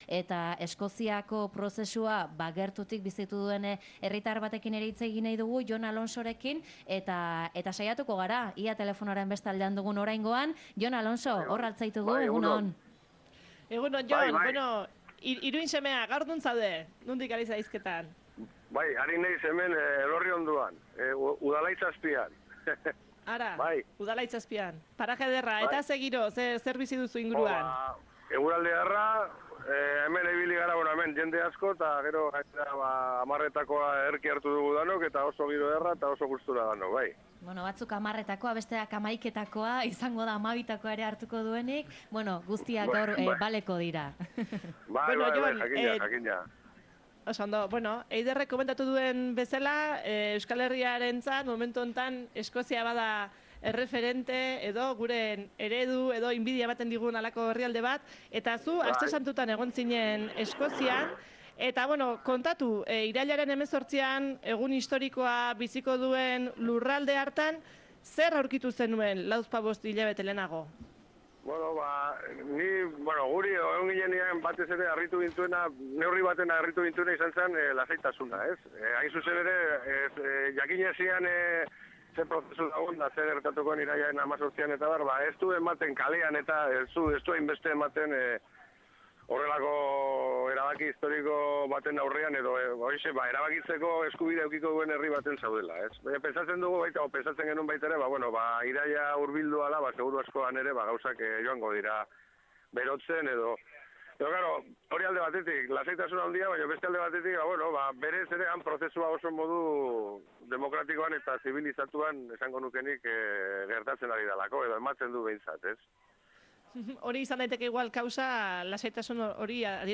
Udalaitz azpitik zuzenean